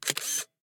screenshoot-shutter.ogg